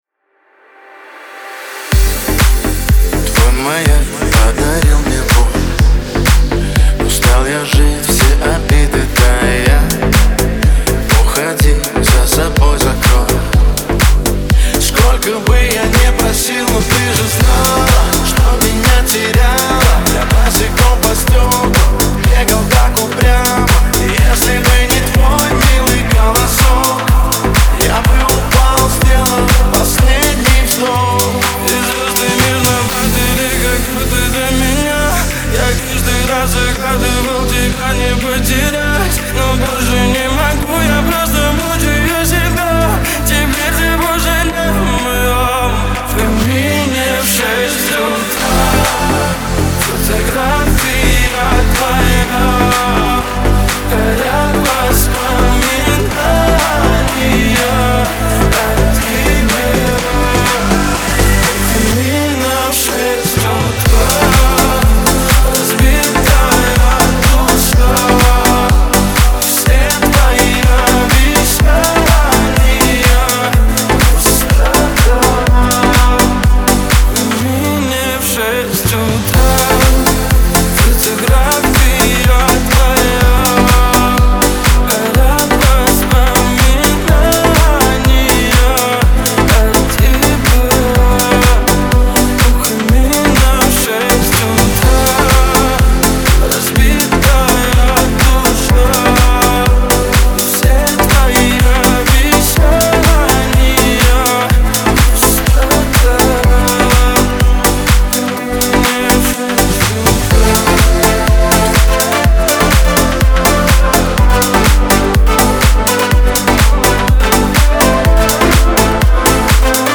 современного поп-музыки с элементами R&B